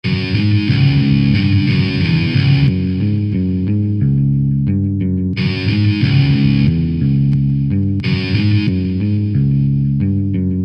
清洁和摇滚的嘻哈吉他循环 90 BPM
Tag: 90 bpm Hip Hop Loops Guitar Electric Loops 1.80 MB wav Key : Unknown